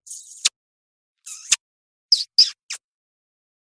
吸い付くようにキスするセクシー音。